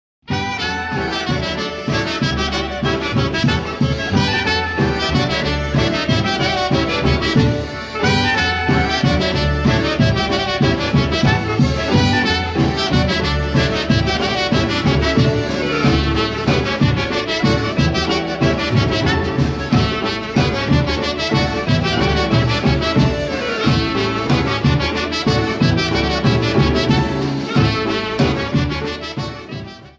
Media > Music > Polka, General